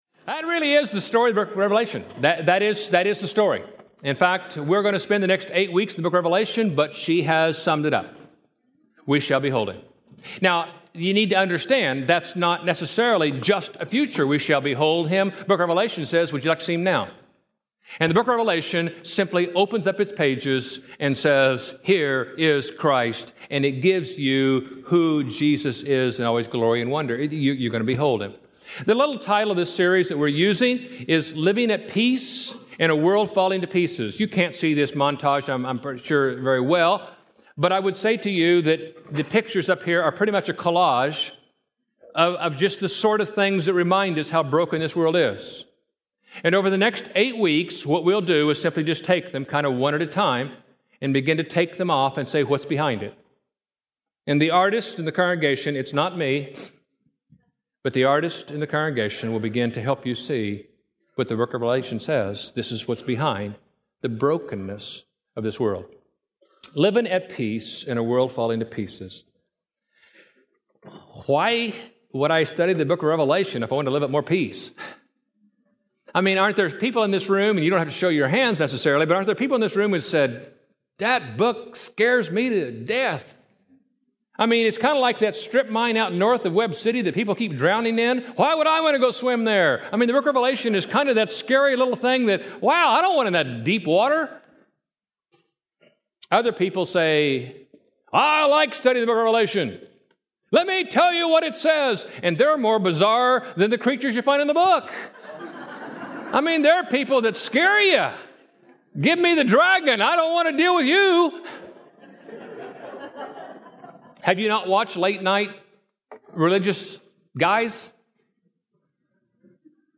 Preached at College Heights Christian Church April 15, 2007 Series: Living at Peace in a World Falling to Pieces Scripture: Revelation 1 Audio